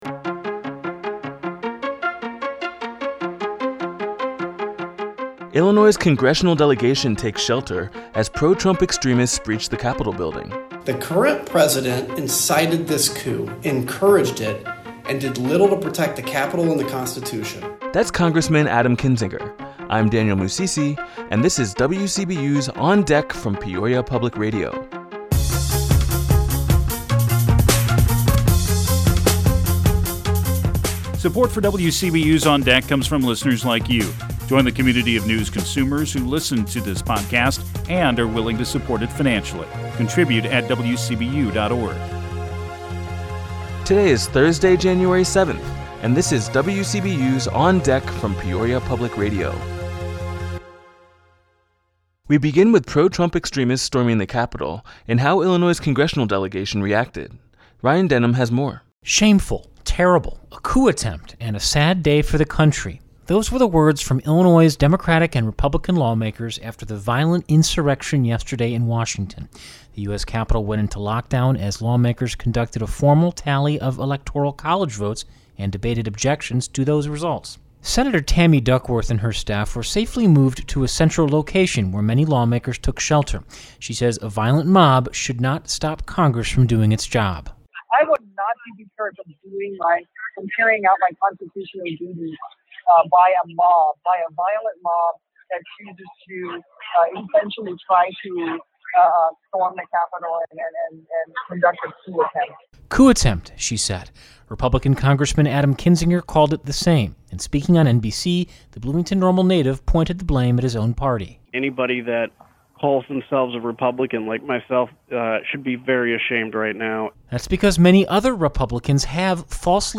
Our top story is about how Illinois' congressional delegation took shelter, as pro-Trump extremists breached the Capitol building. You'll hear how Illinois' congressional delegation reacted. You'll also hear how Governor JB Pritzker has named 17 scholars and educators to the Illinois Holocaust and Genocide Commission, giving a panel that was originally formed in 2010 an entirely new membership.